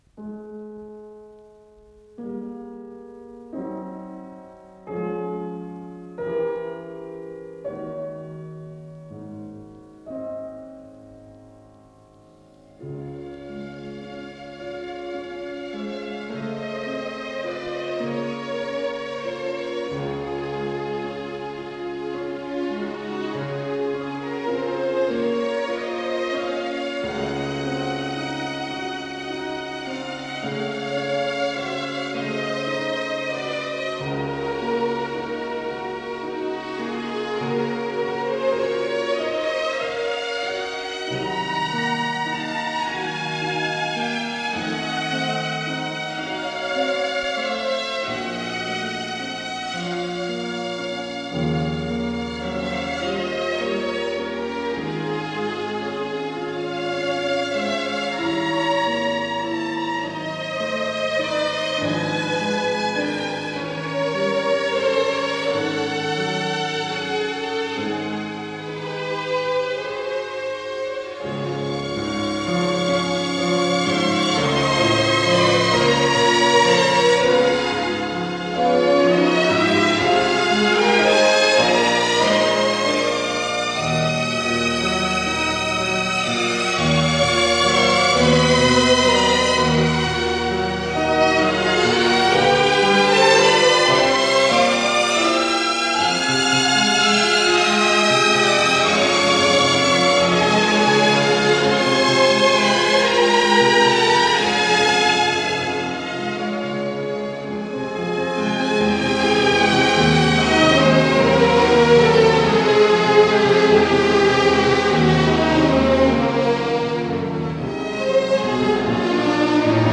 Авторы версииЛ. Фейгин - переложение
ИсполнителиАнсамбль скрипачей Государственного Академического Большого театра СССР
фортепиано